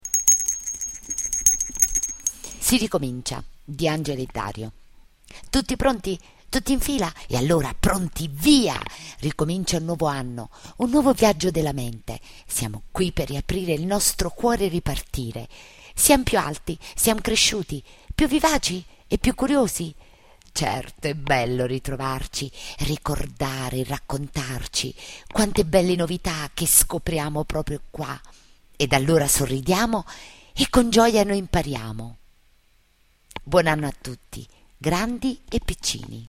Poesie recitate da docenti